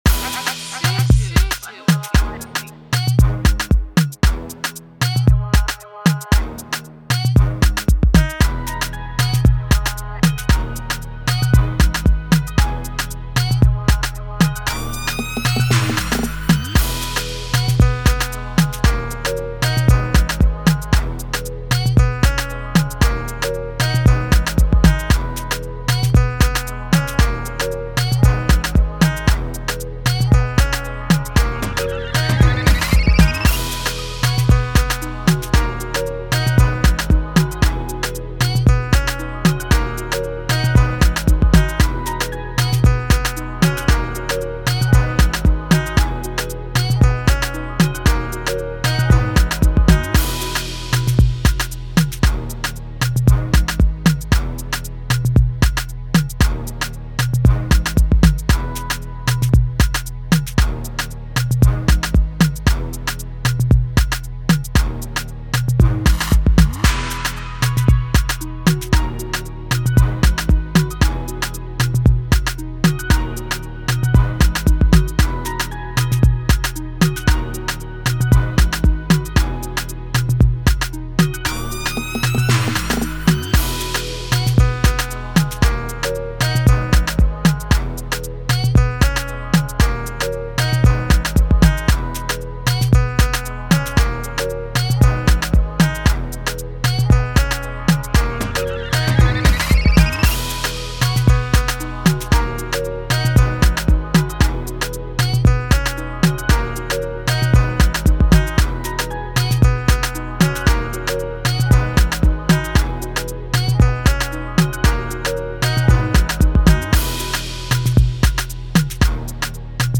banging free beat